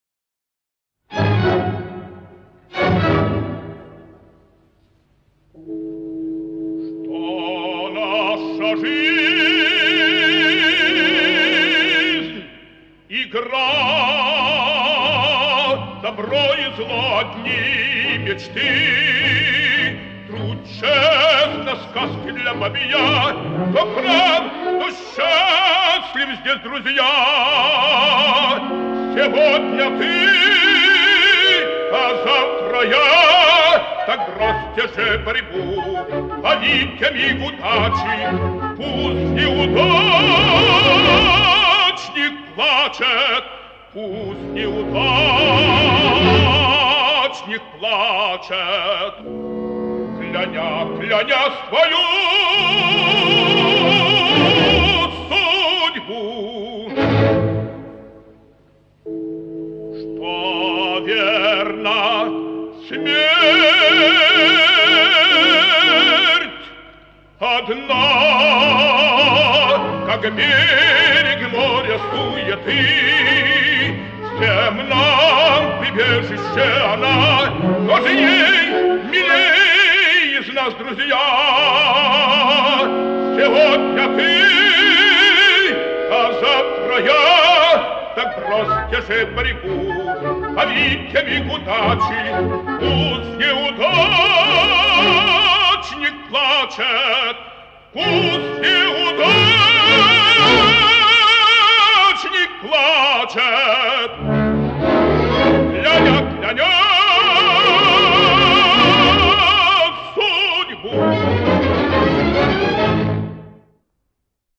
Опера «Пиковая дама». Ария Германа. Оркестр Киевского театра оперы и балета.